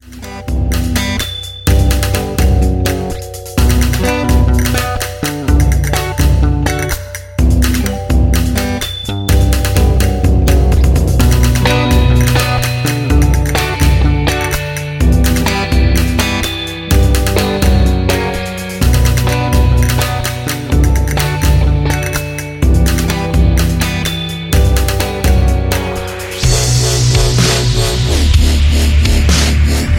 Backing track files: 2010s (1044)